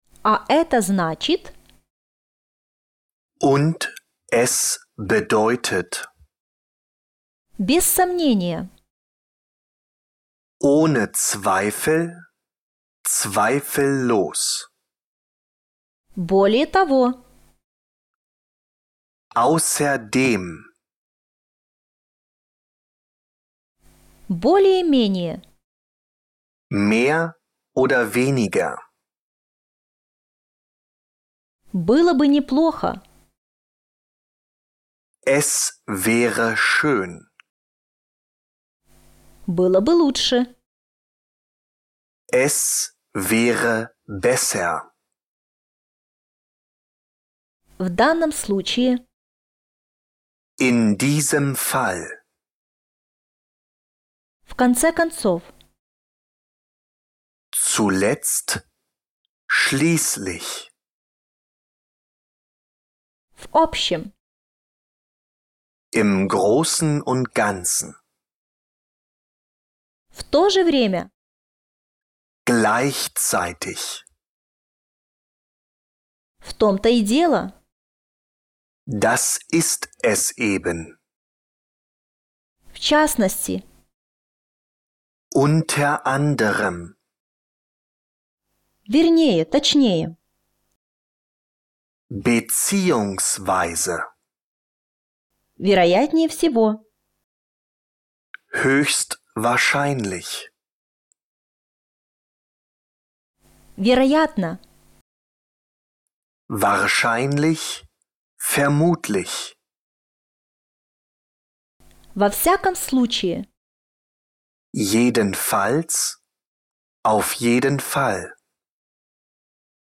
  Прослушайте аудио урок с дополнительными объяснениями